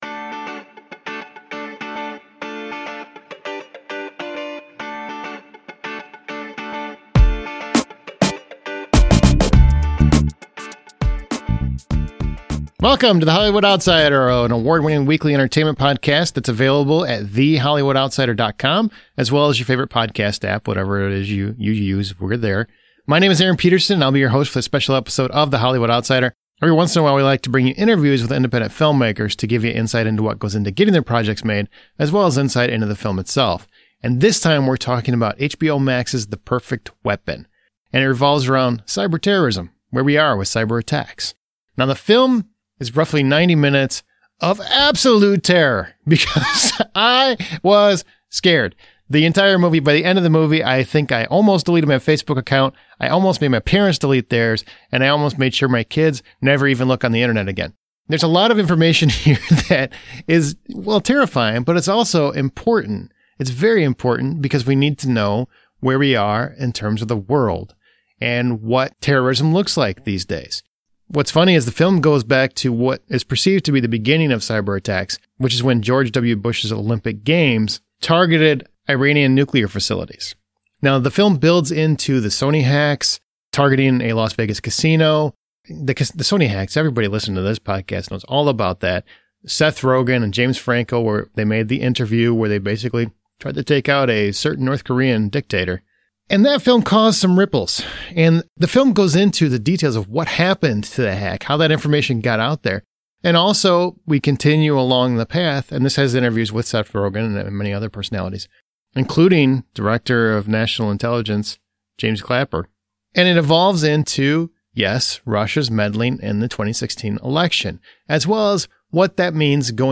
This is a fascinating interview every person needs to hear.